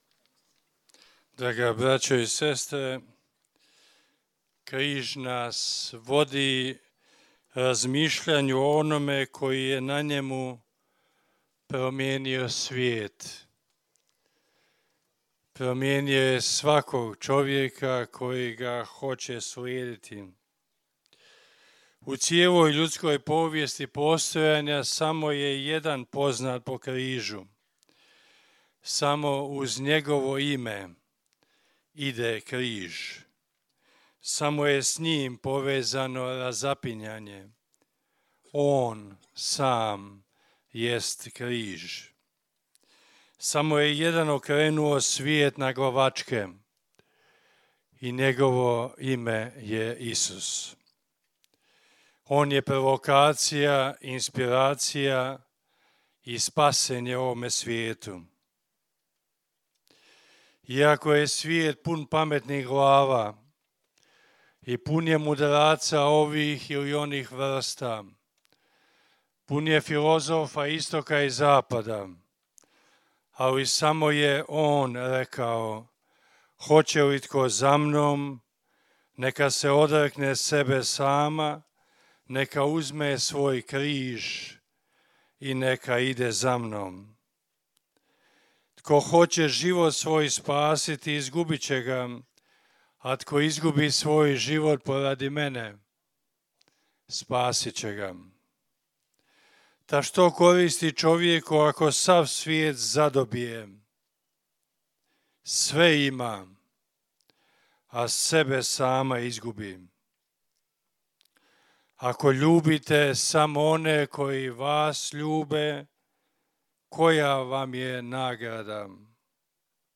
Blagdan Uzvišenja Svetog Križa danas je svečano proslavljen u Međugorju svetom misom na Križevcu.